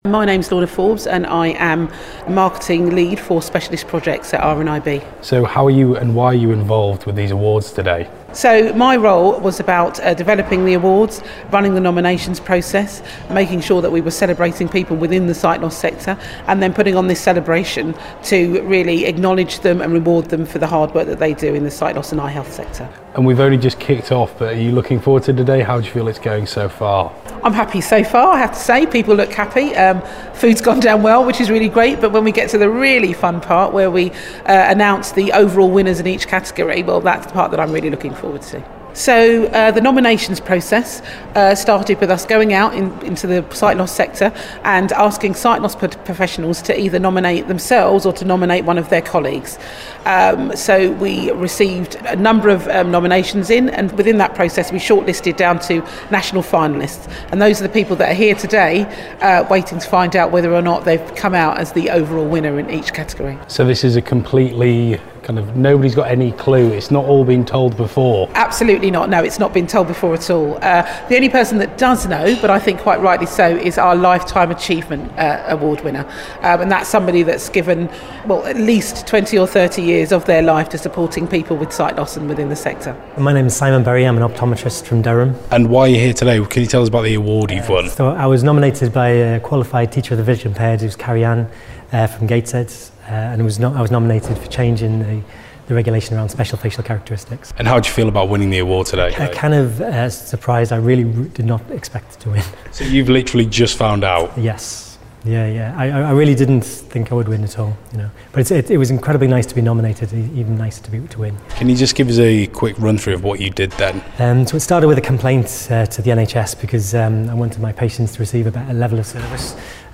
The Royal National Institute of Blind people (RNIB) held it's 2nd awards ceremony in London, earlier this week. The night recognises individuals, teams or groups who have been pivotal in improving the everyday lives of blind or partially sighted people.